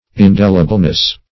In*del"i*ble*ness, n. -- In*del"i*bly, adv.